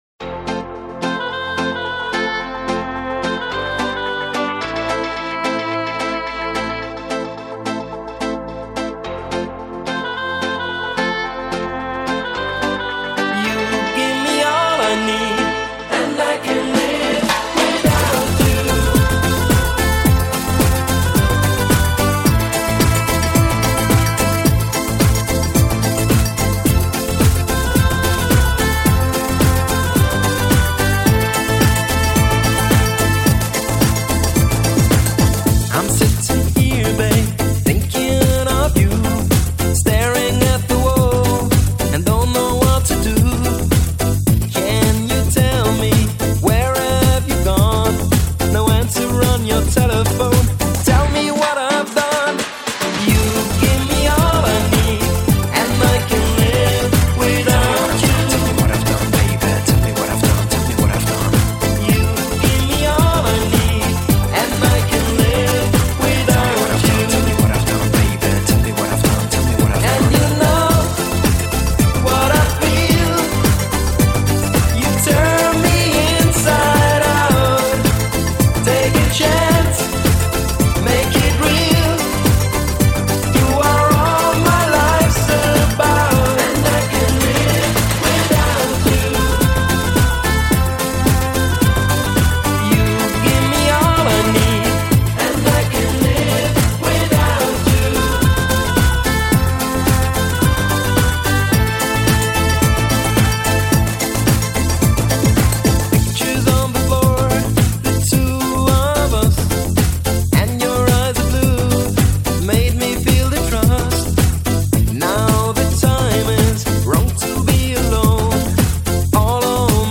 Жанр: Eurodance